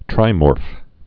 (trīmôrf)